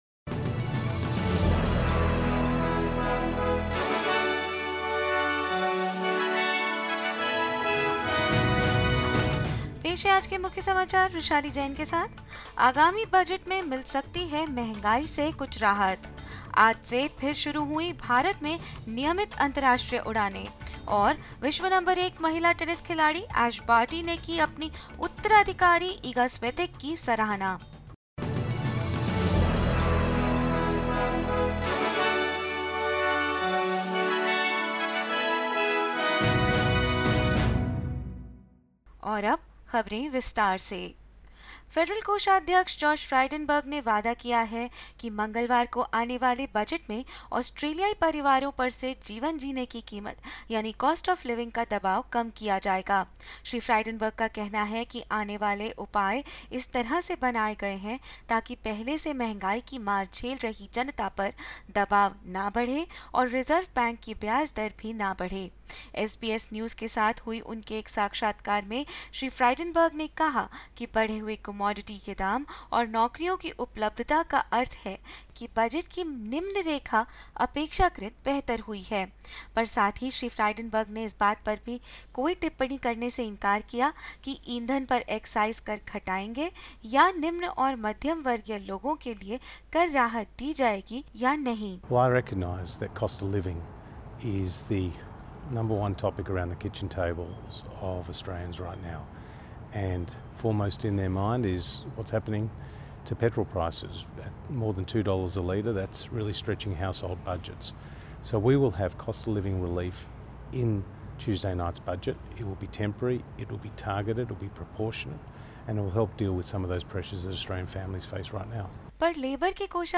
In this latest SBS Hindi bulletin: The Coalition foreshadows budget measures to ease Australian cost of living pressures; Daily COVID-19 cases in India dip below 1500; Retiring tennis star Ash Barty hails her successor Iga Swiatek as women's world number one and more news.